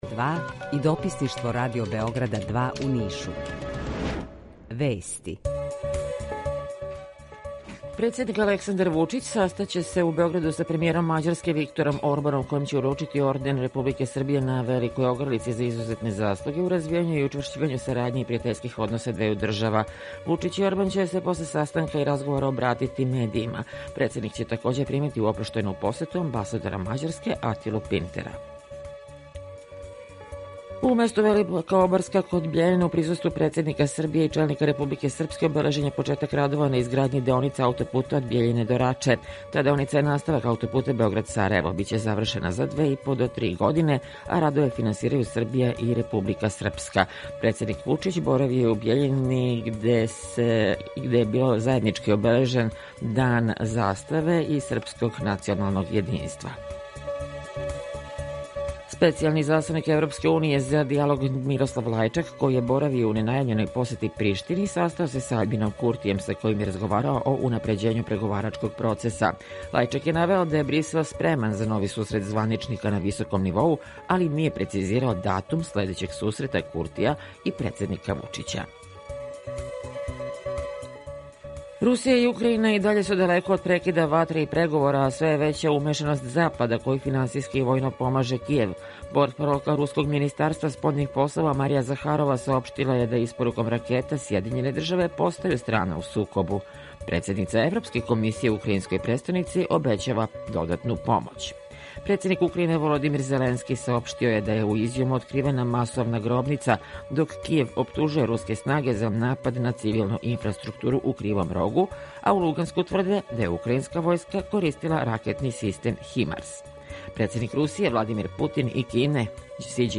Емисију реализујемо заједно са студиом Радија Републике Српске у Бањалуци и са Радио Новим Садом.
Јутарњи програм из три студија
У два сата, ту је и добра музика, другачија у односу на остале радио-станице.